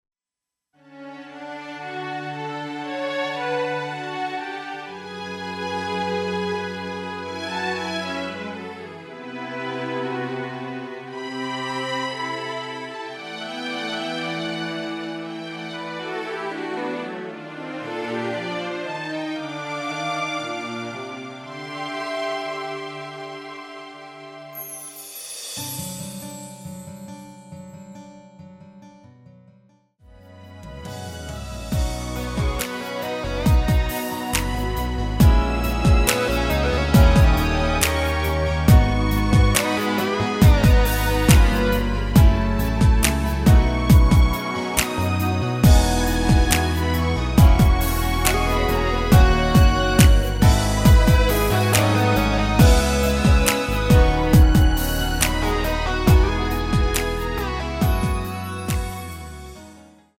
키 G 가수